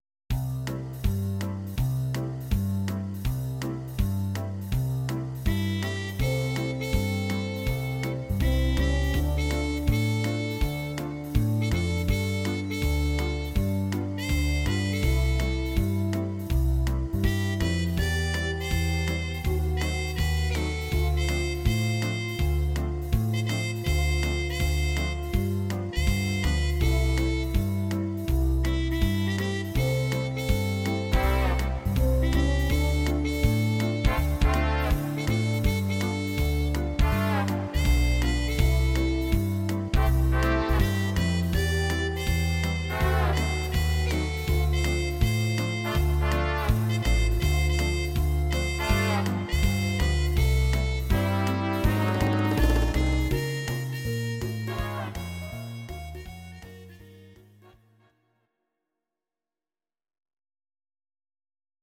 These are MP3 versions of our MIDI file catalogue.
Please note: no vocals and no karaoke included.
Your-Mix: Jazz/Big Band (731)